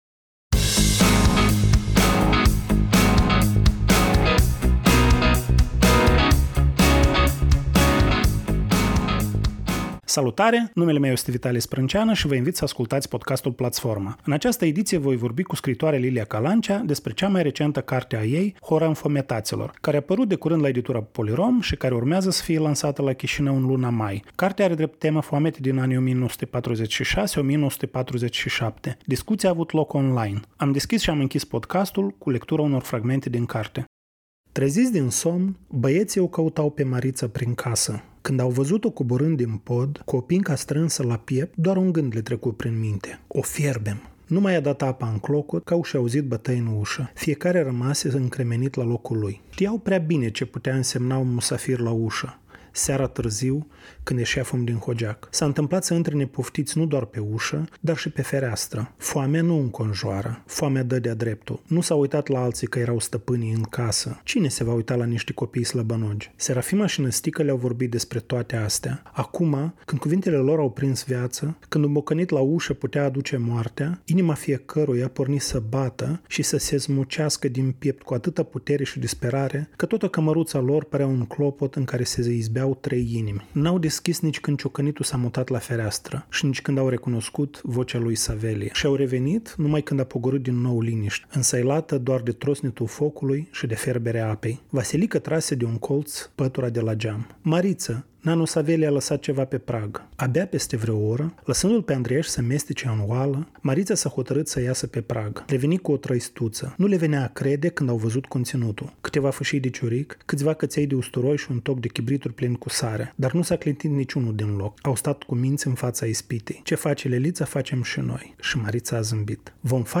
Cartea are drept temă Foametea din Republica Sovietică Socialistă Moldovenească din anii 1946-1947. Discuția a avut loc on-line.